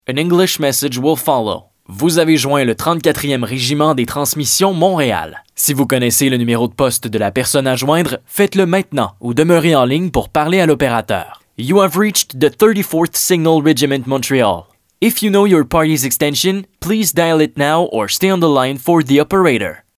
Message téléphonique